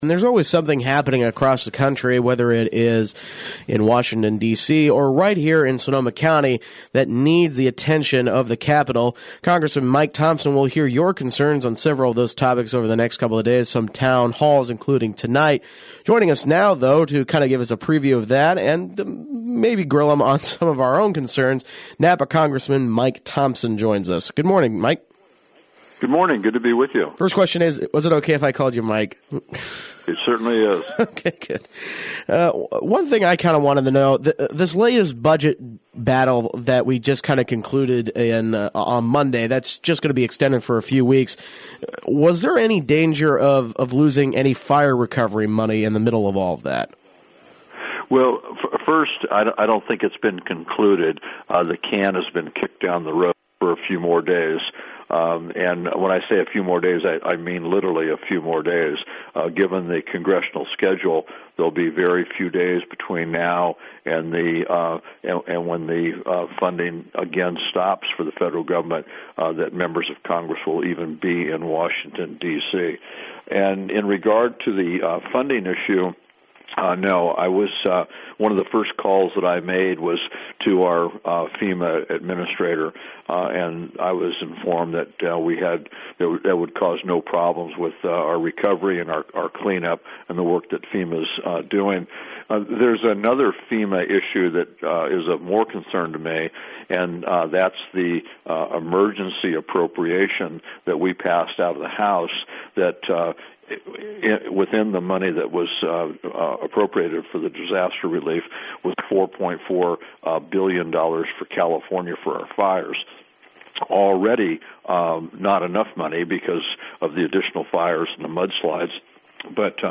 Interview: Upcoming Town Halls with Congressman Mike Thompson
5th District Congressman Mike Thompson, joins us to talk about the upcoming Town Halls he will be holding this week.